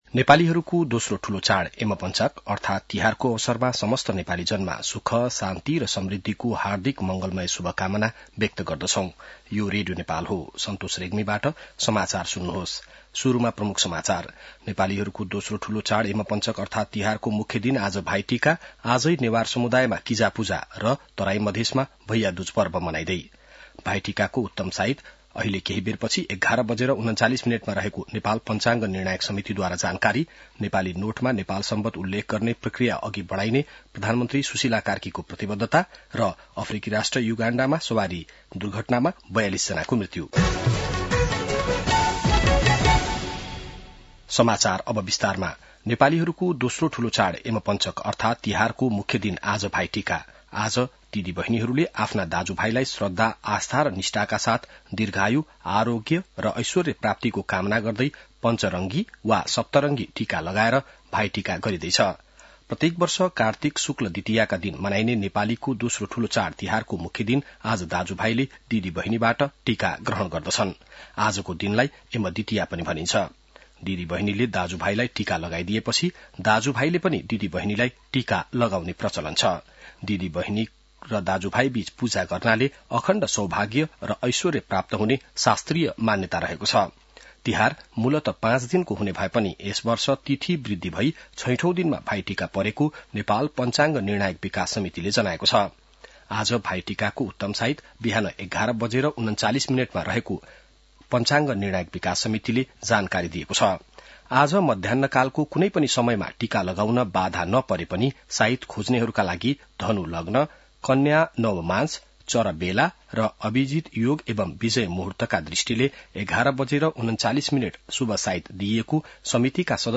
An online outlet of Nepal's national radio broadcaster
बिहान ९ बजेको नेपाली समाचार : ६ कार्तिक , २०८२